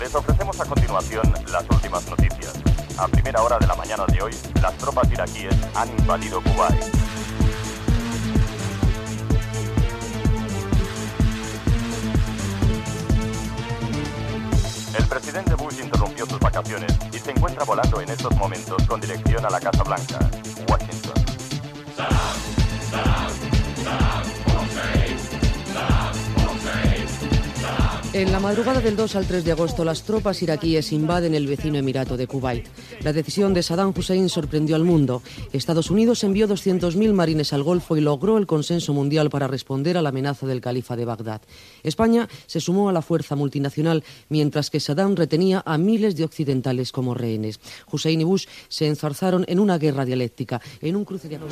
"Los sonidos del 1990" resum informatiu de l'any.
Careta de l'espai i notícia destacada de l'any 1990.